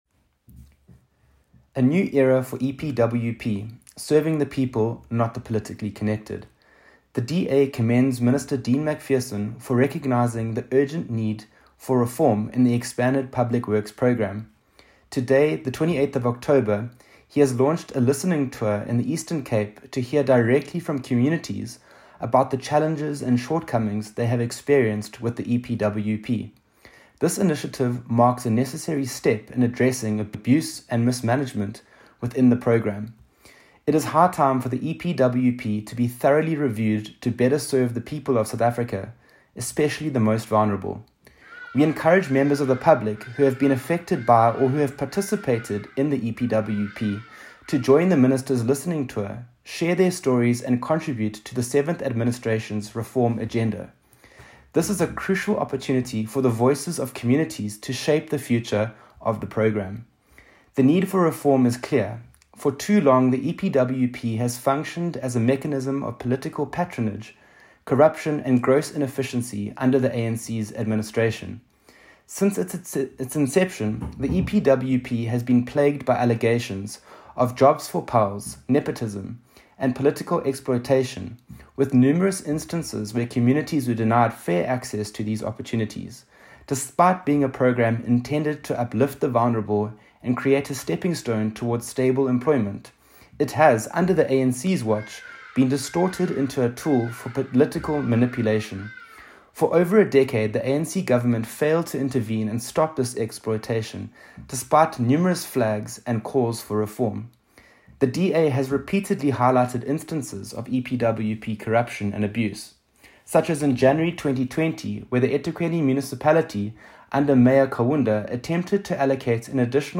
soundbite by Edwin Macrae Bath MP